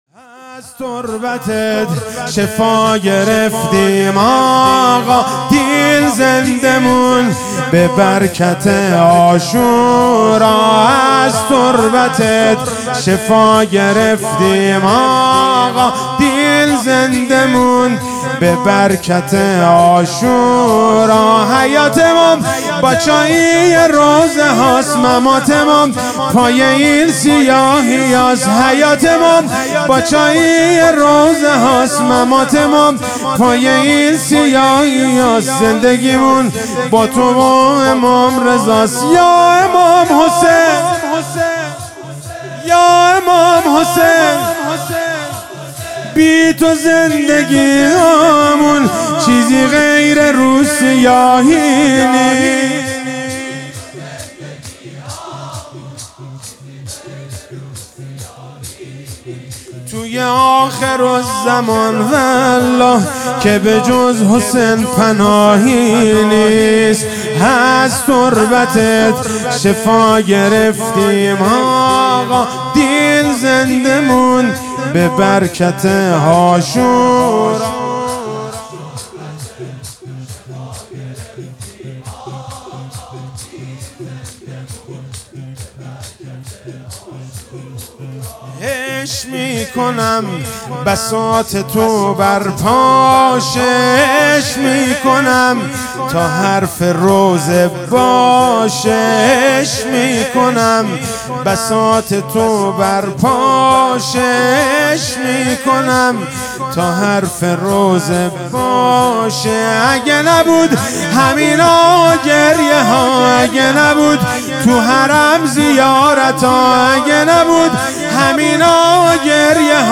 شور - از تربتت شفا گرفتیم آقا